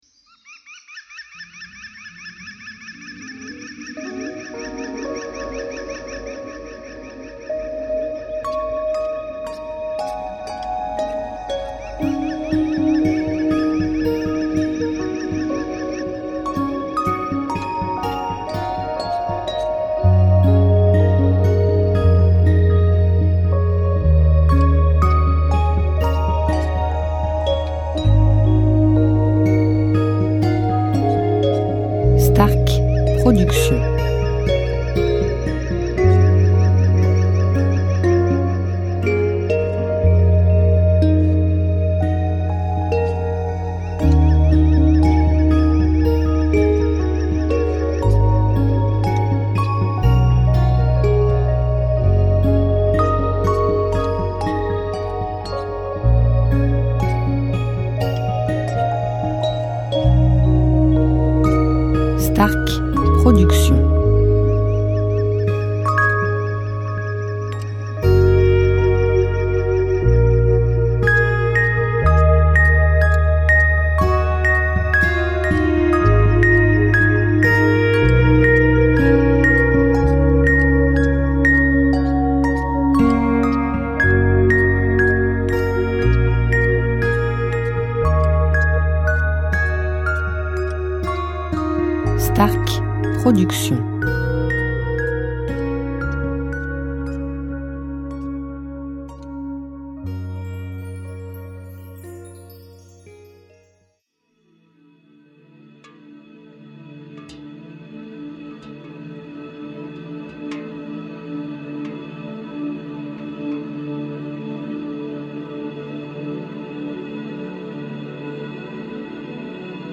style Californien durée 1 heure